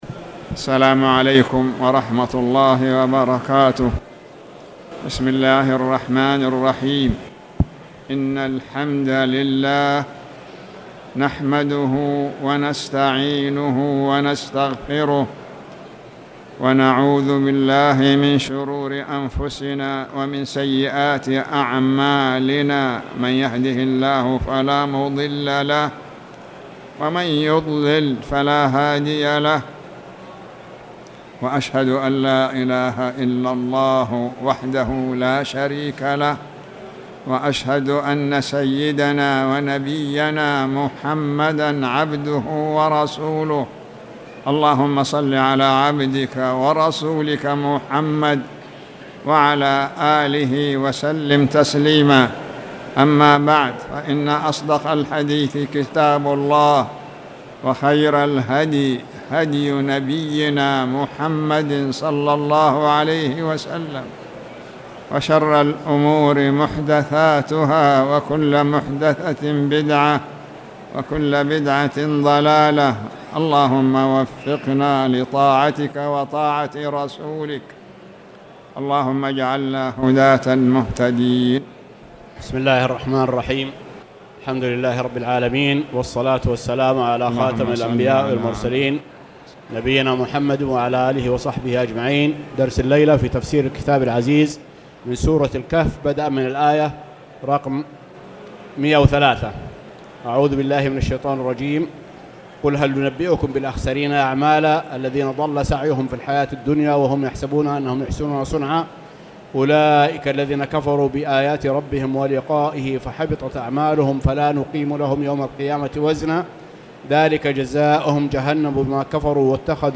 تاريخ النشر ٢٣ صفر ١٤٣٨ هـ المكان: المسجد الحرام الشيخ